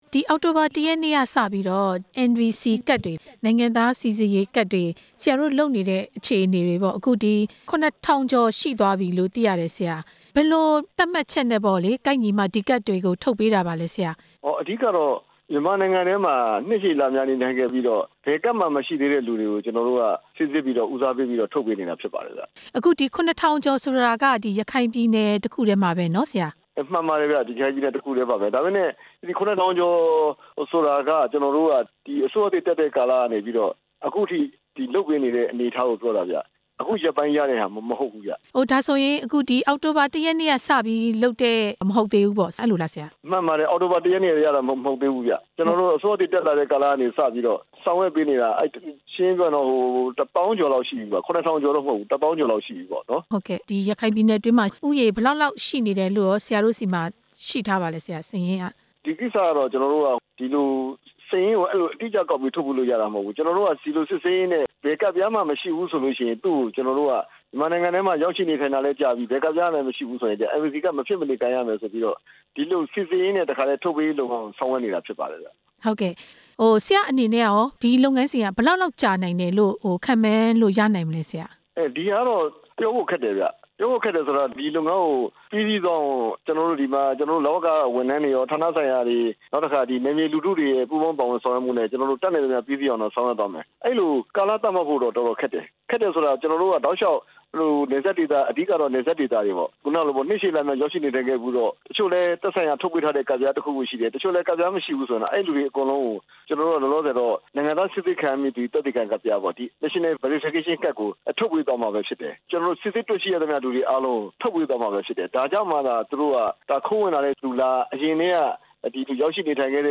ရခိုင်ပြည်နယ်မှာ NVC နိုင်ငံသားစိစစ်ရေးကဒ်ရရှိသူ နောက်ဆုံးအခြေအနေ မေးမြန်းချက်